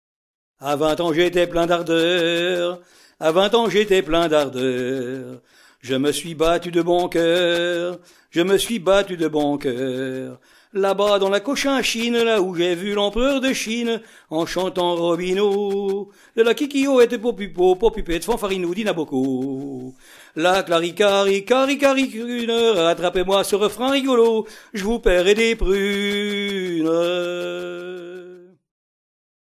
Genre strophique
Pièce musicale éditée